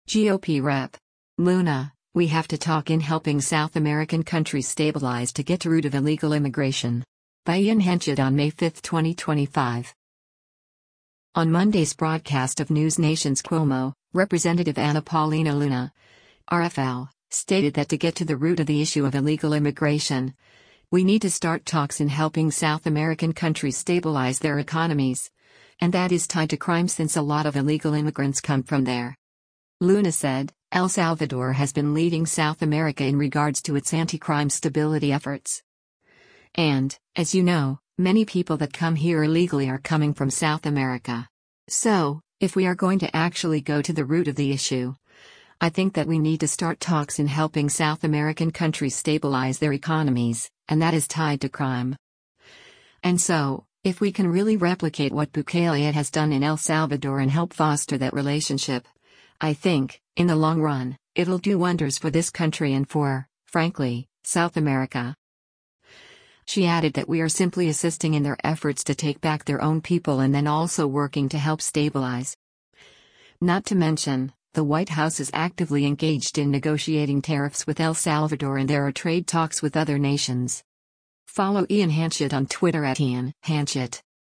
On Monday’s broadcast of NewsNation’s “Cuomo,” Rep. Anna Paulina Luna (R-FL) stated that to get to the “root” of the issue of illegal immigration, “we need to start talks in helping South American countries stabilize their economies, and that is tied to crime” since a lot of illegal immigrants come from there.